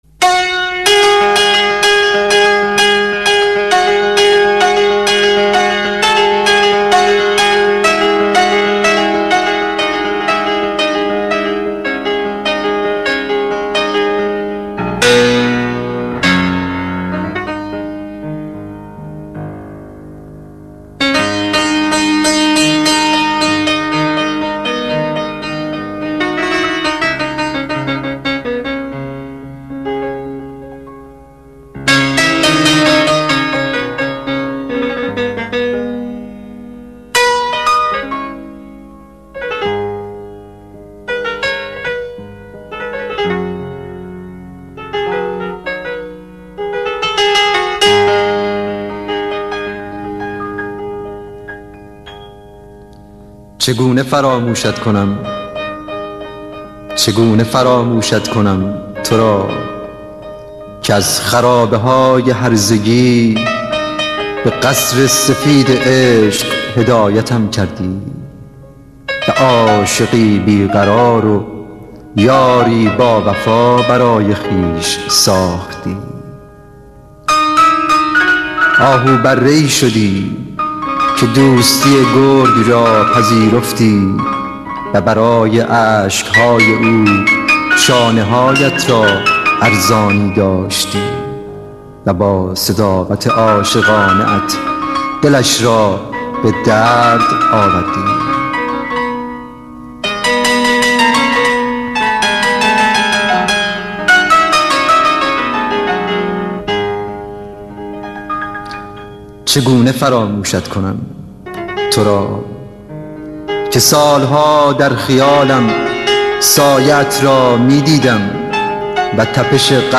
دانلود دکلمه قصه